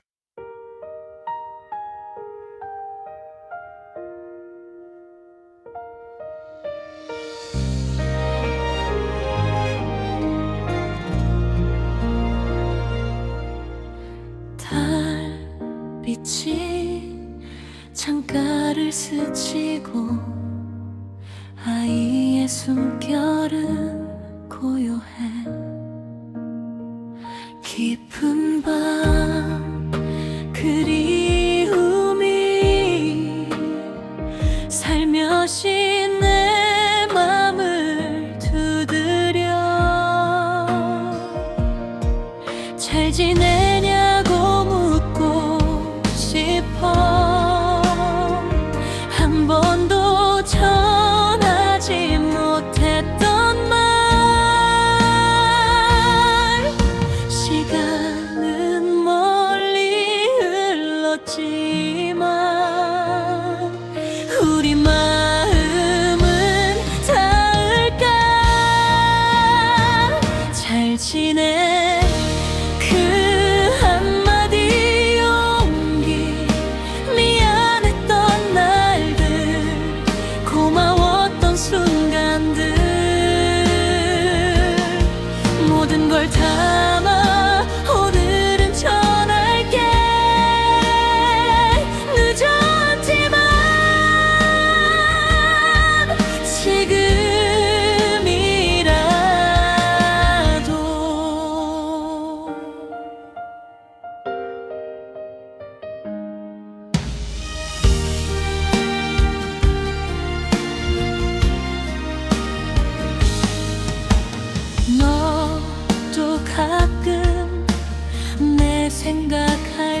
생성된 음악
연락하지 못했던 시간들을 탓하지 않고, 오늘의 마음을 솔직히 건네는 노래 Suno 생성 가이드 (참고) Style of Music Lullaby, Slow Tempo, Soft Piano, Minimal, Female Vocals, Soft Voice Lyrics Structure [Meta] Language: Korean Topic: “잘 지내?”